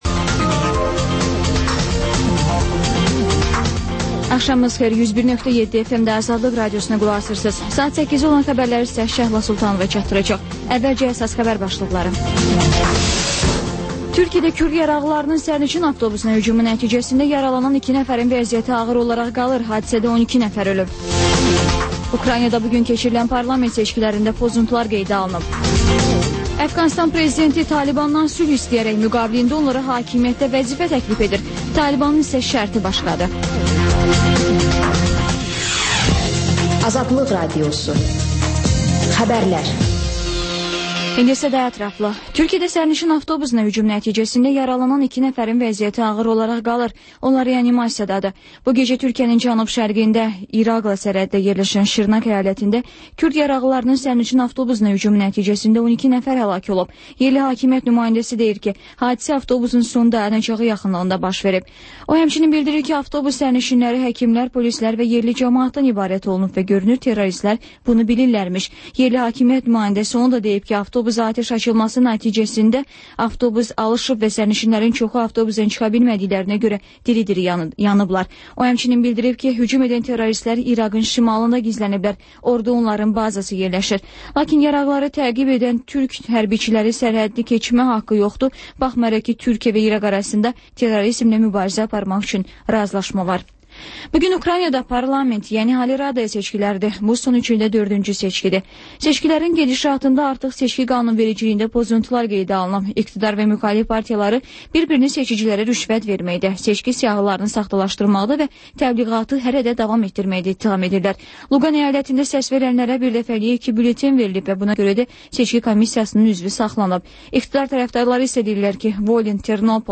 Xəbərlər, İZ: Mədəniyyət proqramı və TANINMIŞLAR rubrikası: Ölkənin tanınmış simalarıyla söhbət